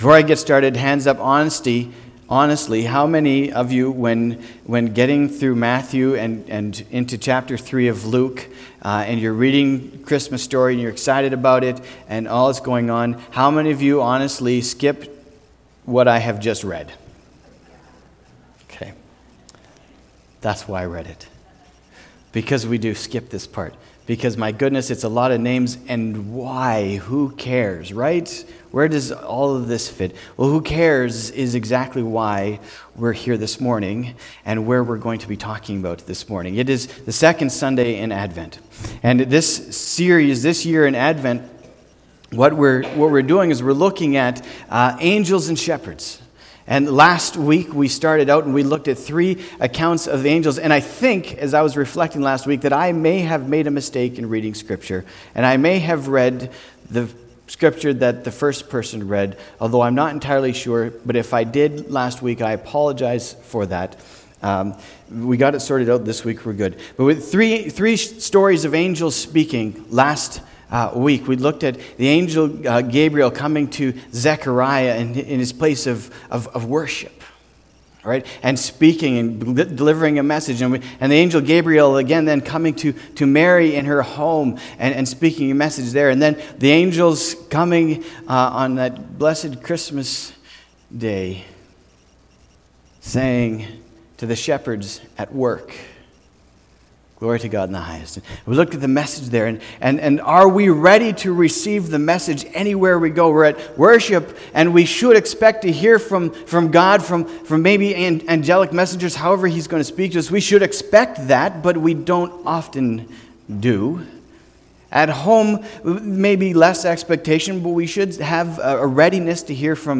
december-8-2019-sermon.mp3